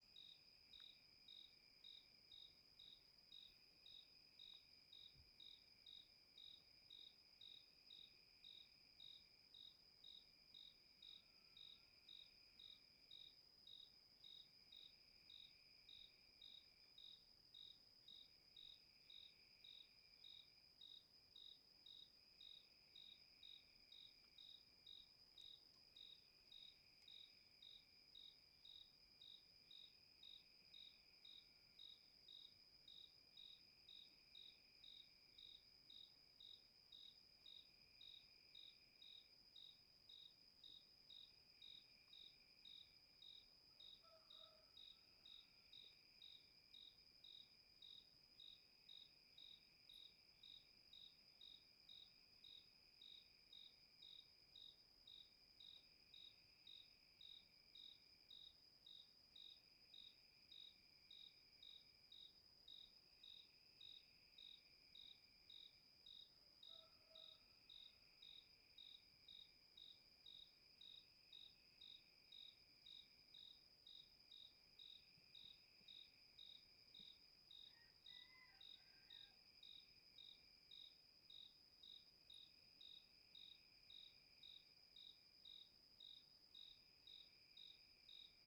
Grilos de perto a noite Grilo , Insetos , Noite , Rural
Stereo
CSC-13-004-LE - Grilos de perto a noite.wav